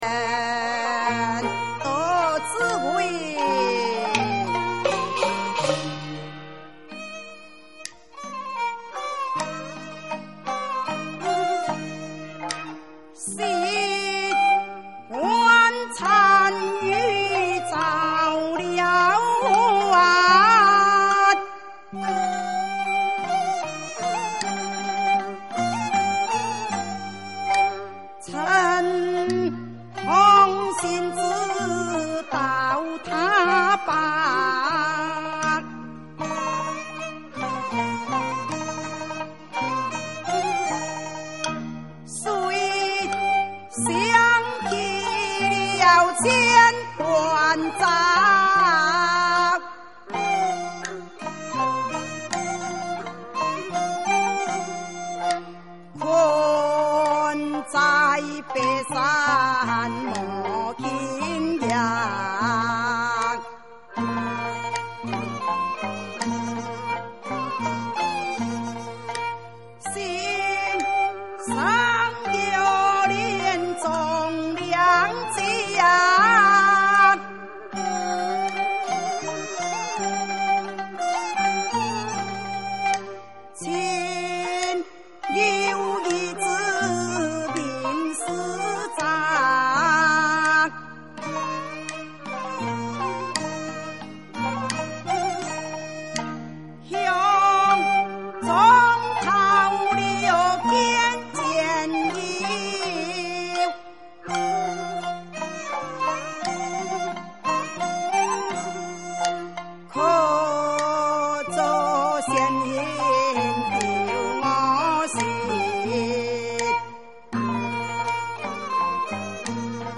蘇武牧羊【四空門】 北管古路戲曲